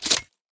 select-shotgun-2.ogg